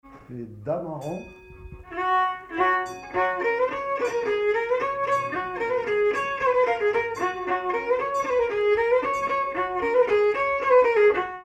Mazurka partie 4
danse : mazurka
circonstance : bal, dancerie
Pièce musicale inédite